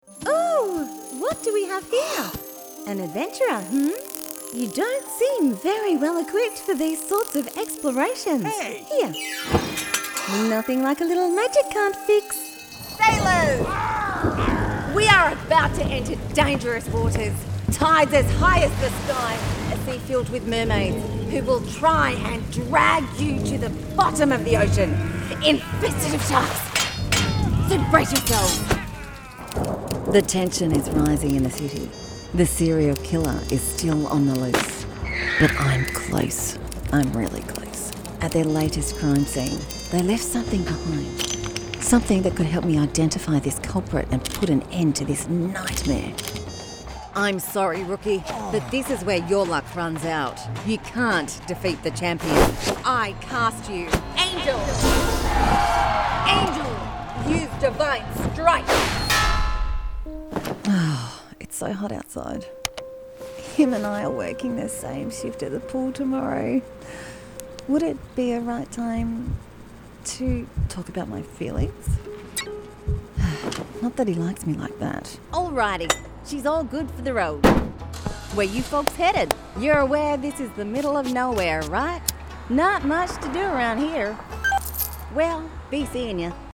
Female
Australian English (Native)
Approachable, Bright, Conversational
Microphone: Rode NT1-AI, Neuman TLM 103
Audio equipment: Fully treated pro home studio space, dual walled top of the range Whisper Room Voice Over Booth, Rode NT1-AI Mic, Rode interface, Windows desktop computer located outside the booth, share screen inside the booth, source connect standard studio